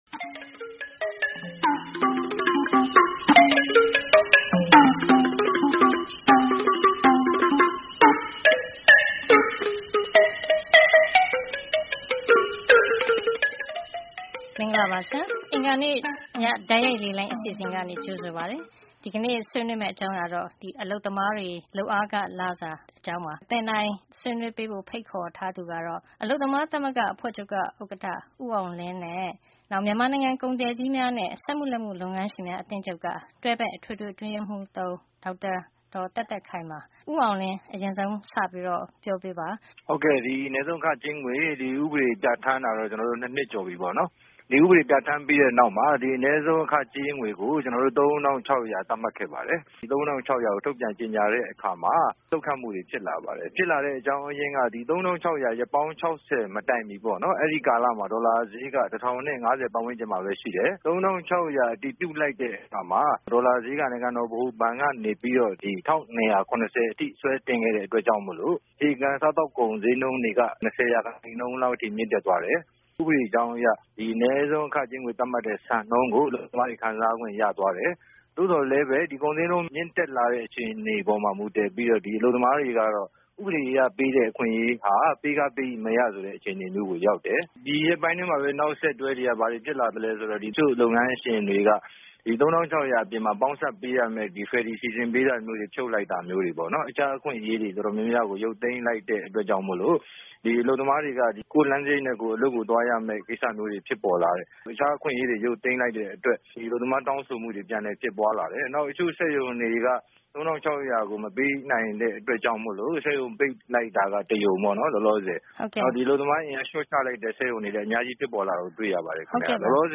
09-08-15 Tue Call In- Impact of Minimum Wage (MT)